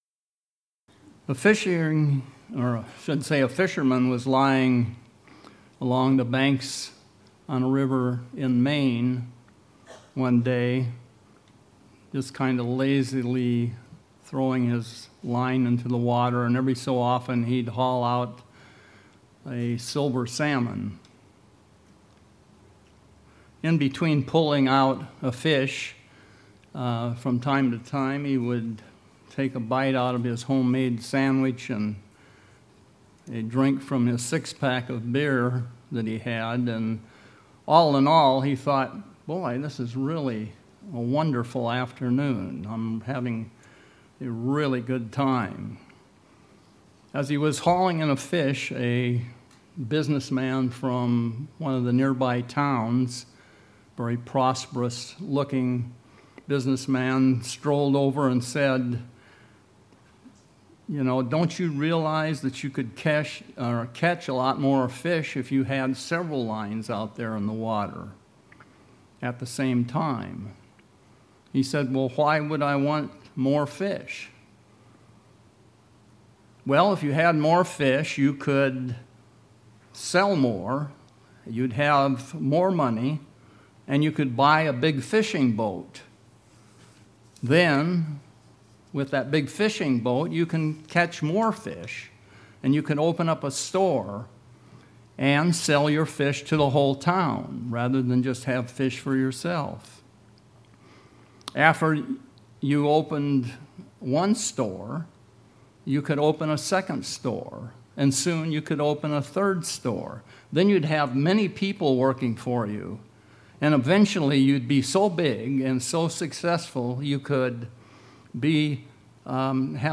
Sermons
Given in Seattle, WA